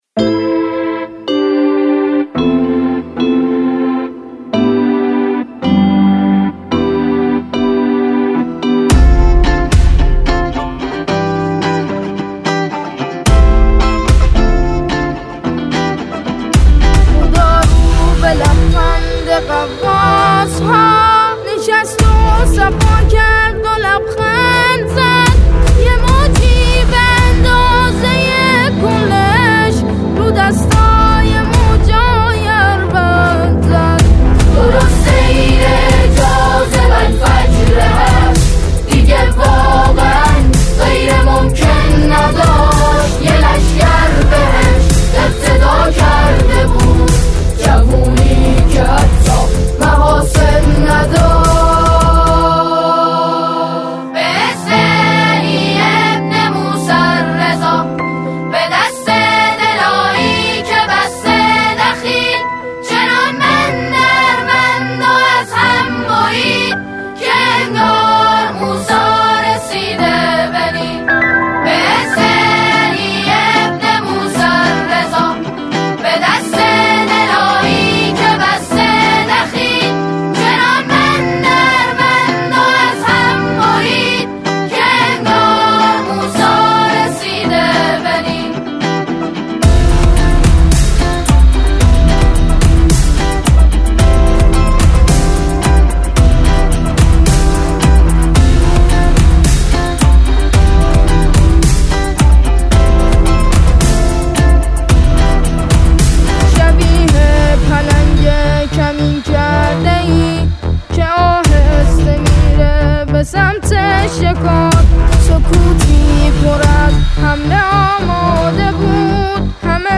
آنها در این قطعه، شعری را درباره دفاع مقدس همخوانی می‌کنند.